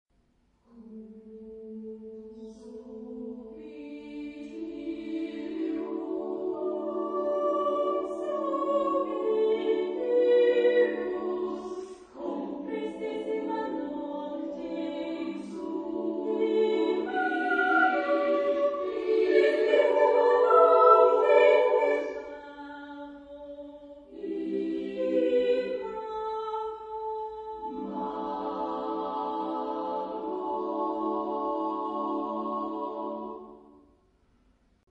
Genre-Style-Forme : Madrigal ; Profane
Type de choeur : SSAA  (4 voix égales de femmes )
Solistes : Sopran (1)  (1 soliste(s))
Tonalité : libre